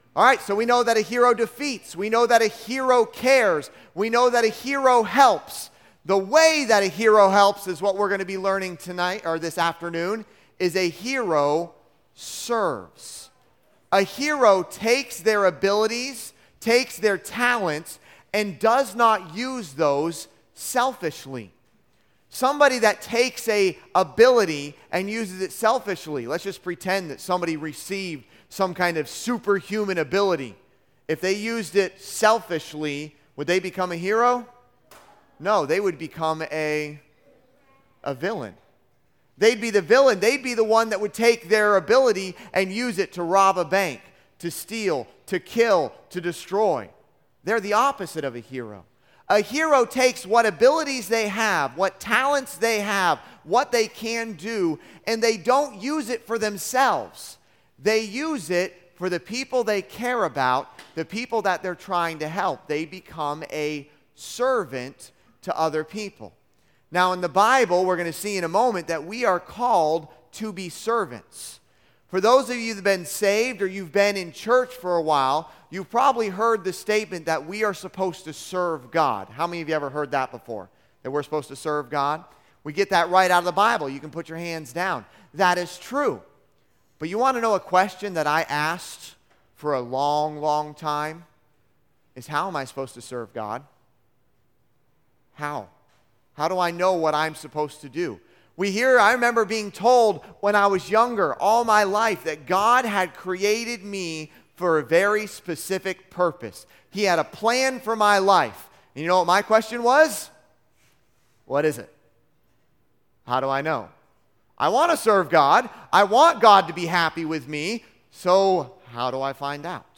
Service Type: Junior Camp